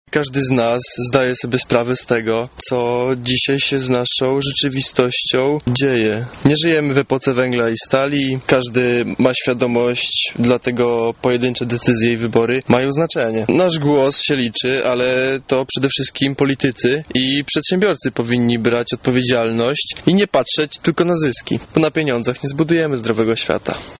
uczestnik Cichego protestu dla klimatu